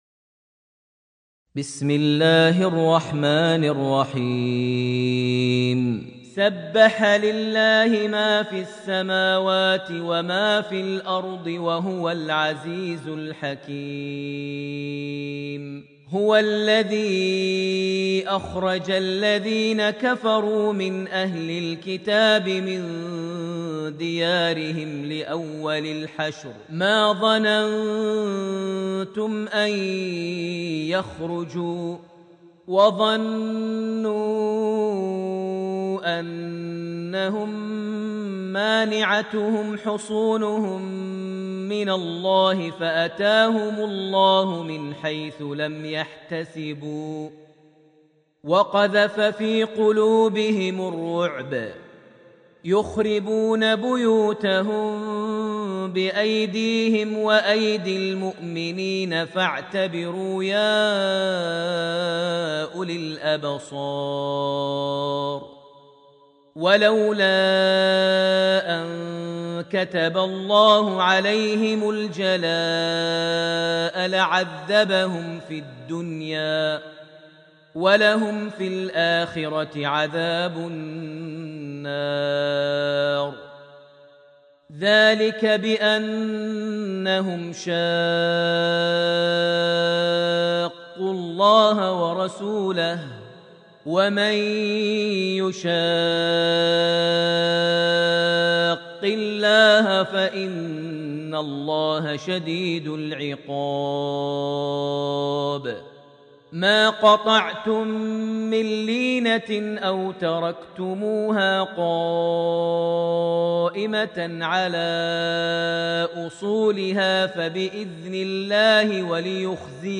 سورة الحشر > المصحف المجوَّد ( سُجِّلَ في مُجمع الملك فهد لطباعة المصحف ) > المصحف - تلاوات ماهر المعيقلي